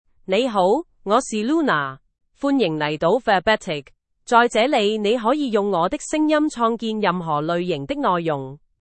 Luna — Female Chinese (Cantonese, Hong Kong) AI Voice | TTS, Voice Cloning & Video | Verbatik AI
Luna is a female AI voice for Chinese (Cantonese, Hong Kong).
Voice sample
Listen to Luna's female Chinese voice.
Luna delivers clear pronunciation with authentic Cantonese, Hong Kong Chinese intonation, making your content sound professionally produced.